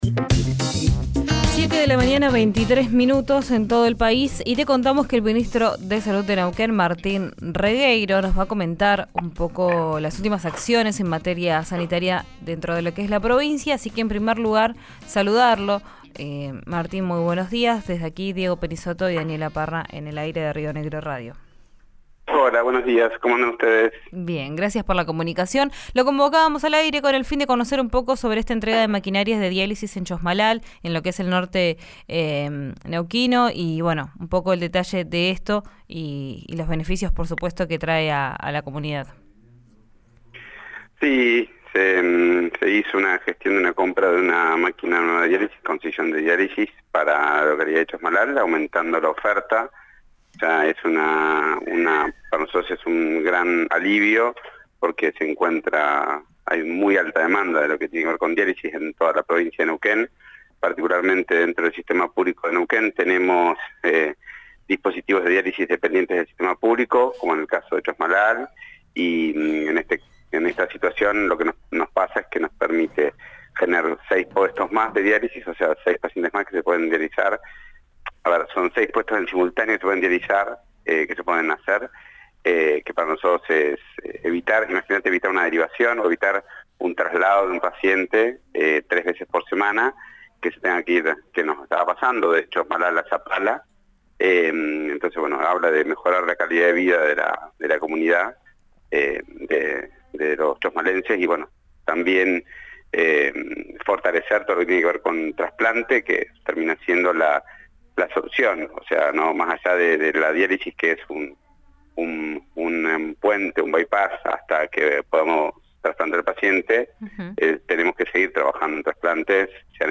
Lo anunció el ministro de Salud, Martín Regueiro, esta mañana en RIO NEGRO RADIO.
Escuchá a Martín Rigueiro, ministro de Salud de Neuquén, en RÍO NEGRO RADIO: